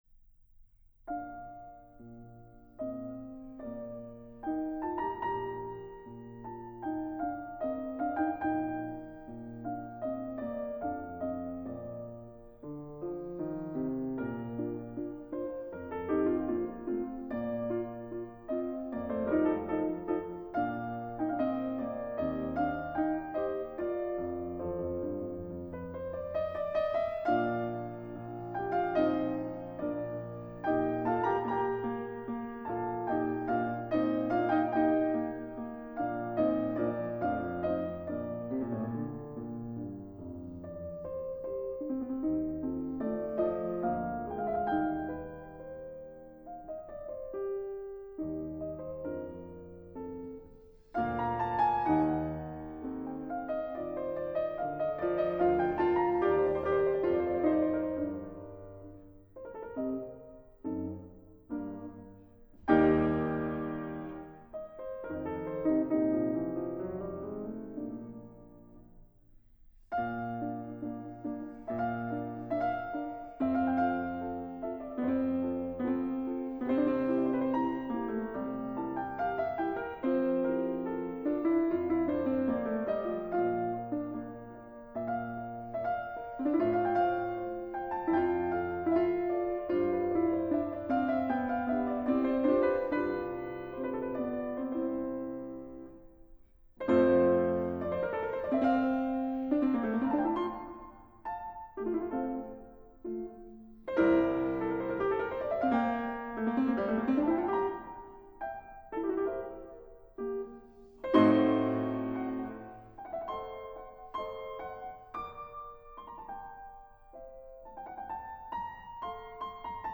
for piano 4 hands